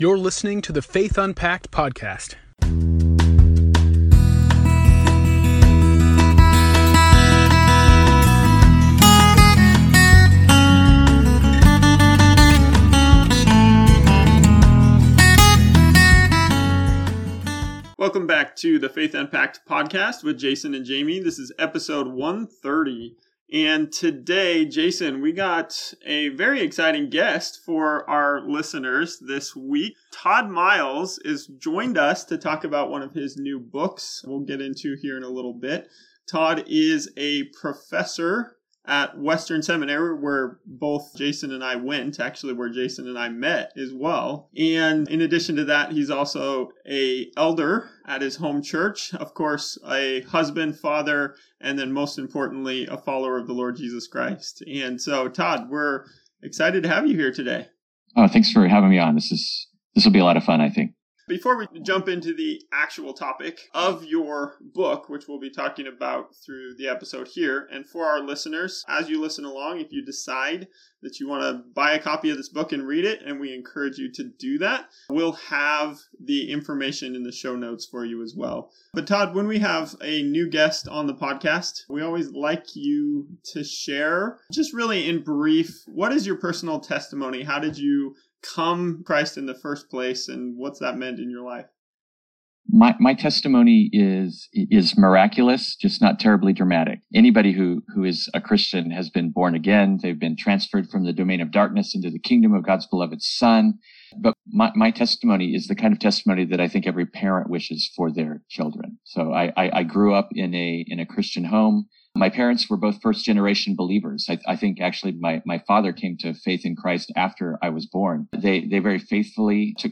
Episode 130: Christian and Marijuana – An Interview